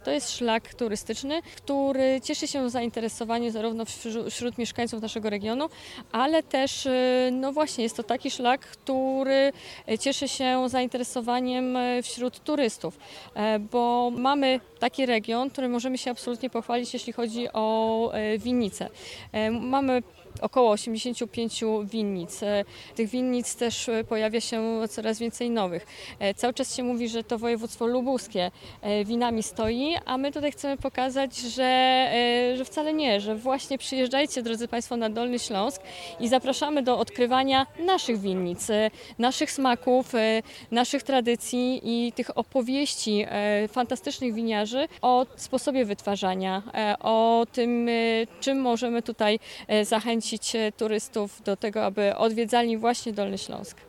Na Dolnym Śląsku tę formę turystyki można realizować podróżując Dolnośląskim Szlakiem Piwa i Wina, o którym opowiada Natalia Gołąb – członkini zarządu województwa dolnośląskiego.